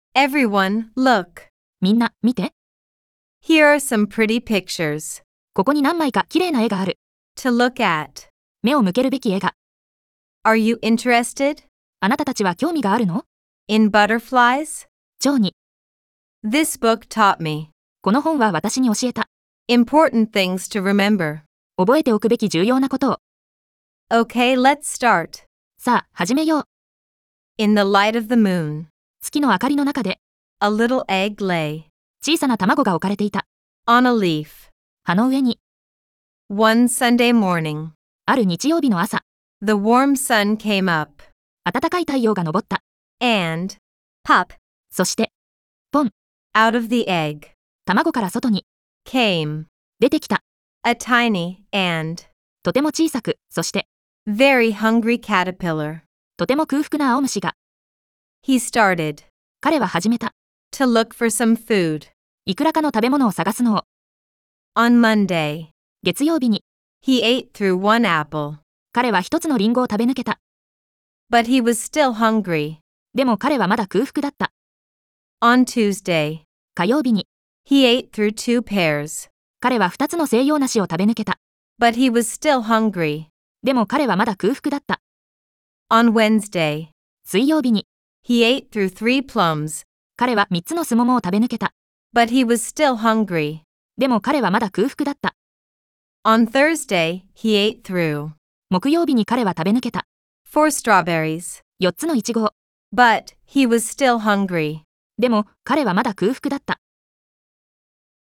♪ 習得用の音声(英⇒日を区切りごと)：